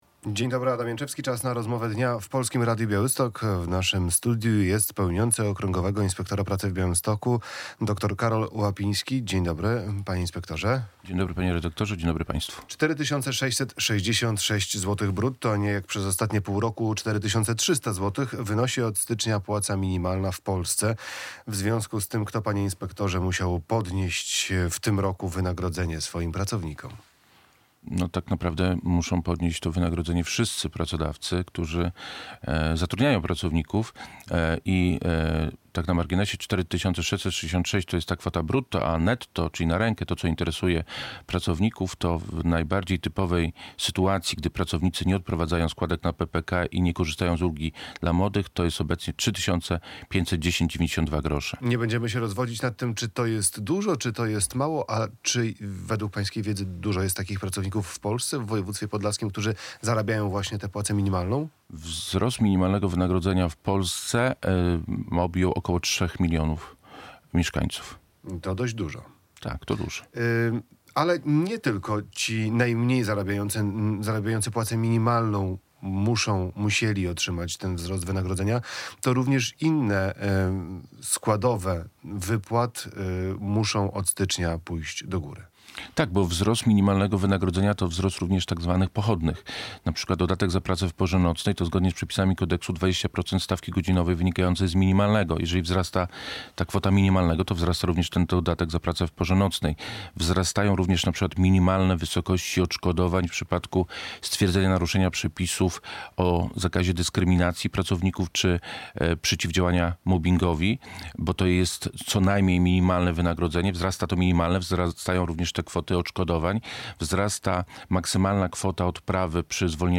Radio Białystok | Gość | Karol Łapiński - p.o. Okręgowego Inspektora Pracy w Białymstoku